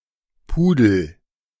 The Poodle, called the Pudel in German (German: [ˈpuːdl̩]
De-Pudel.ogg.mp3